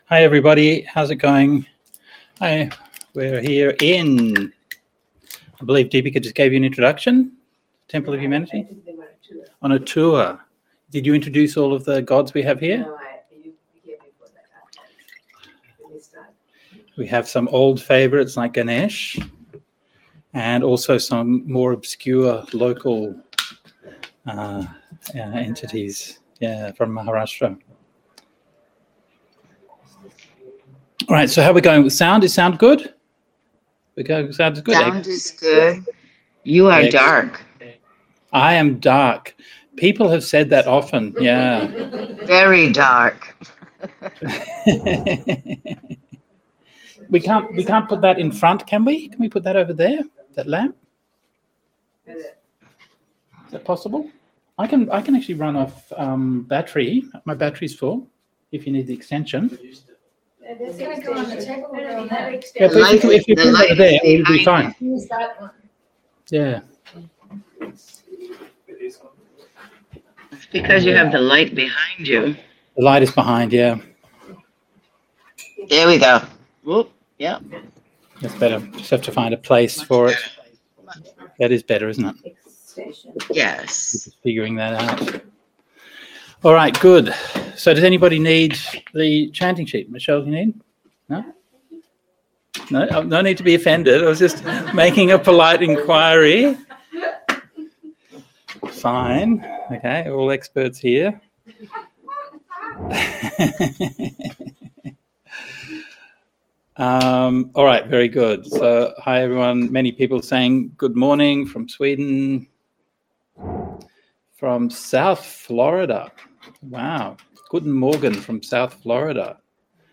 Meditation on mindfulness and contentment in the present. Dhamma talk on Nagarjuna's work Mūlamadhyamakakārikā. Part eight of rains series on Dependent Origination.